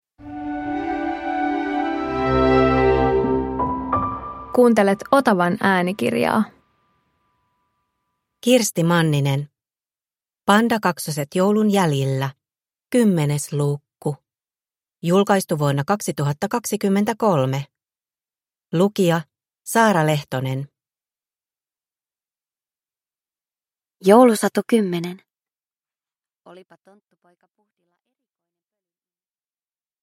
Pandakaksoset joulun jäljillä 10 – Ljudbok